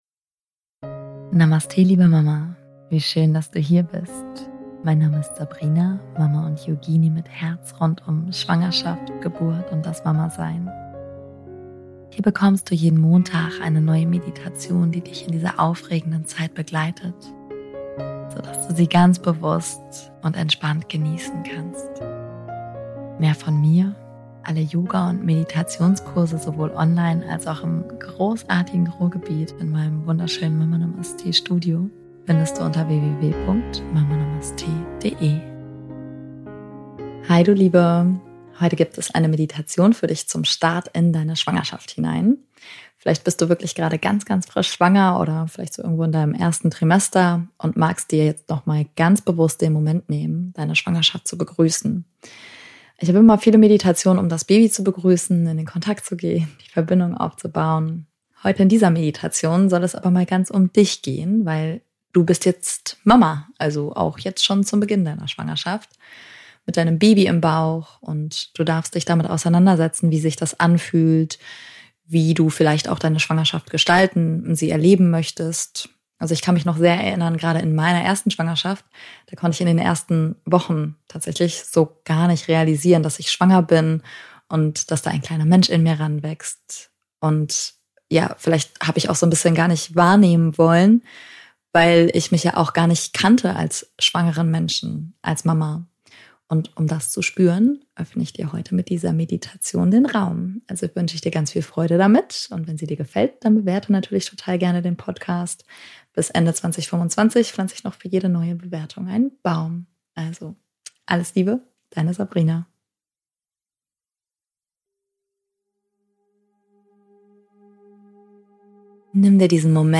Beschreibung vor 4 Monaten Meditation zum Start in deine Schwangerschaft In dieser Folge erwartet dich eine liebevolle Meditation für den Beginn deiner Schwangerschaft – ideal, wenn du gerade ganz frisch schwanger bist oder dich im ersten Trimester befindest. Diesmal geht es ganz um dich: darum, in deiner neuen Rolle als werdende Mama anzukommen, zu spüren, was sich verändert, und bewusst in deine Schwangerschaft hineinzuwachsen.